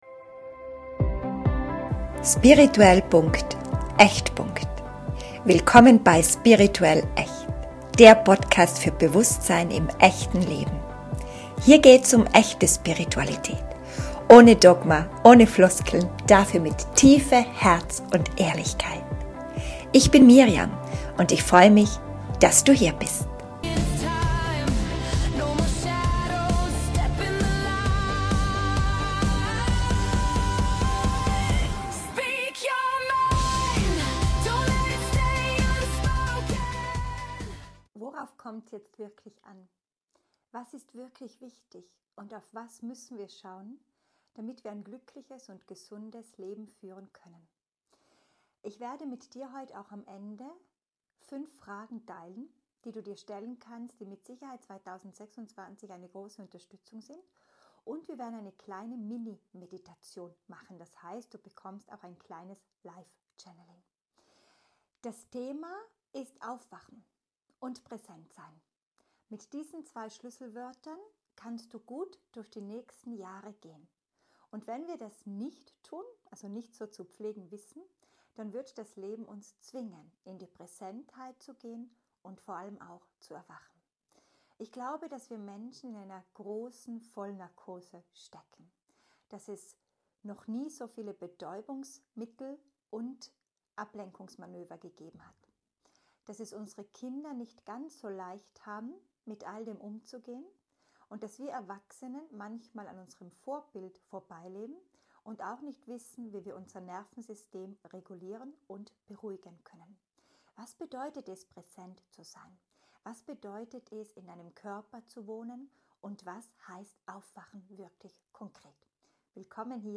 In dieser Episode erwarten dich: eine ehrliche Reflexion über Ablenkung & Betäubung tiefe Impulse zu Aufwachen, Präsenz & Bewusstsein fünf kraftvolle Fragen für deinen Weg in die kommenden Jahre eine geführte Mini-Meditation / Live-Channeling zum Ankommen im Hier & Jetzt Diese Folge ist eine sanfte, aber klare Erinnerung daran, dass du nicht funktionieren musst, um wertvoll zu sein – sondern da sein darfst.